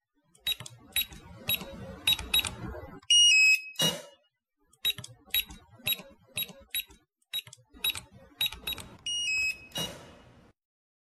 Tiếng bíp bíp Bấm Mật Mã mở khóa cửa
Thể loại: Tiếng chuông, còi,  Tiếng đồ vật
Description: Âm thanh “bíp bíp” vang lên, tiếng nhấn nút nhẹ nhàng như bản nhạc điện tử ngắn báo hiệu thao tác nhập mã. Hiệu ứng âm thanh bấm mật mã mở khóa cửa mang lại cảm giác công nghệ, an toàn và hiện đại. Tiếng điện tử, tiếng nhấp, tiếng xác nhận...
tieng-bip-bip-bam-mat-ma-mo-khoa-cua-www_tiengdong_com.mp3